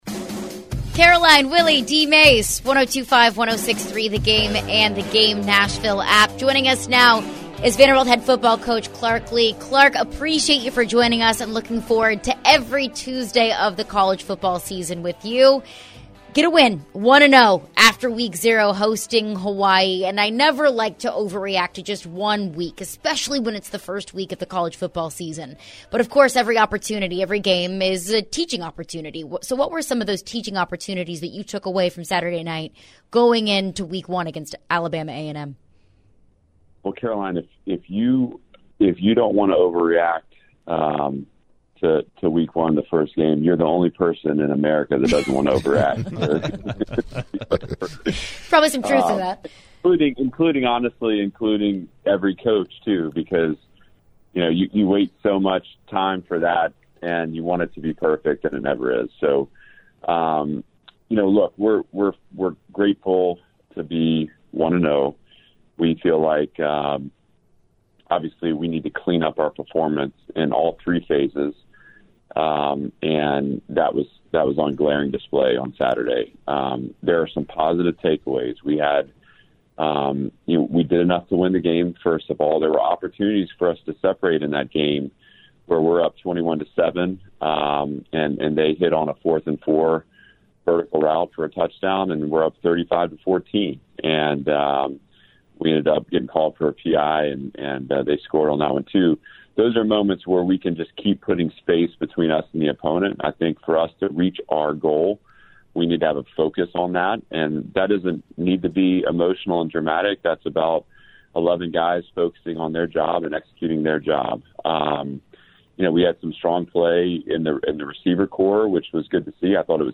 Vanderbilt head coach Clark Lea joined the show to recap his view of week one's win over Hawaii.